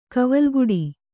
♪ kavalguḍi